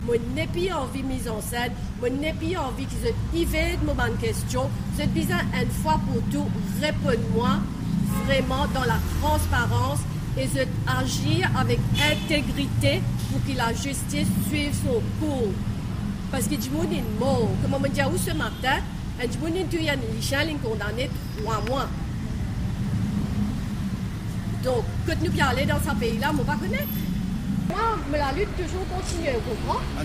Elle a réuni la presse, ce mardi 29 août, devant la cour intermédiaire.